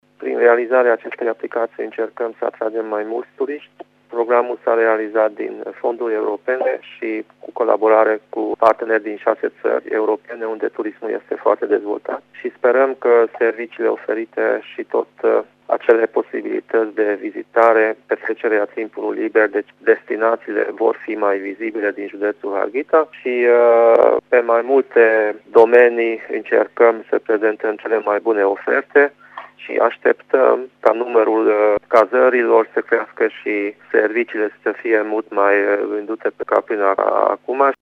Președintele Consiliului Județean Harghita, Borboly Csaba: